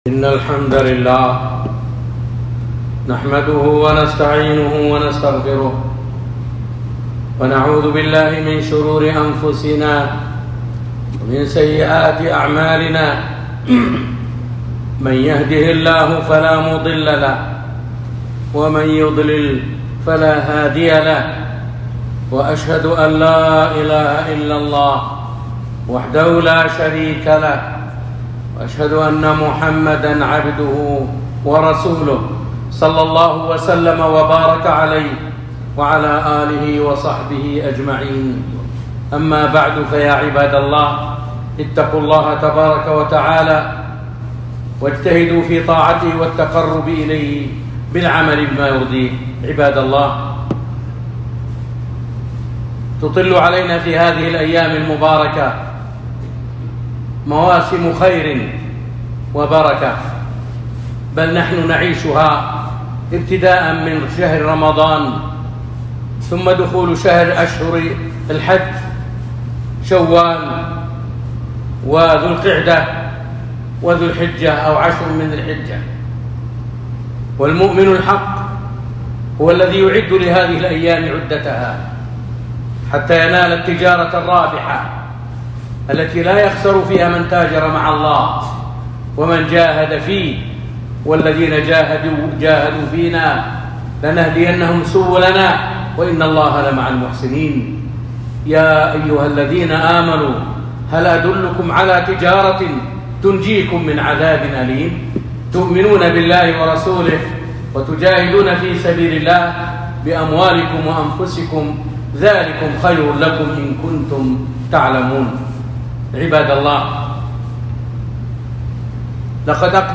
خطبة فضل عشر ذي الحجة 1443